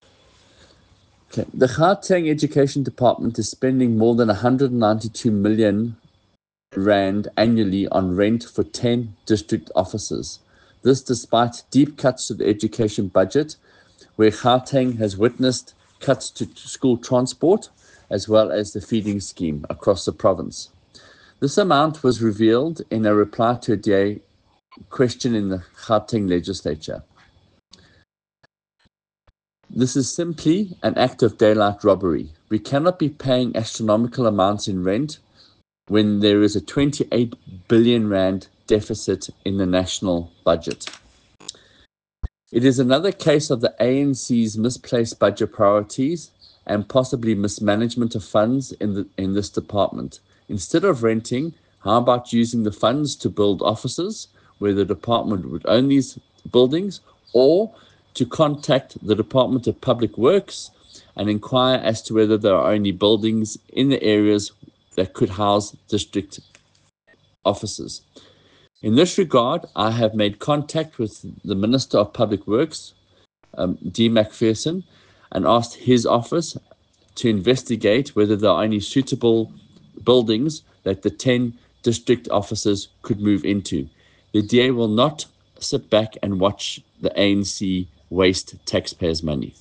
Note to Editors: Please find an English sounbite from DA MPL, Michael Waters MPL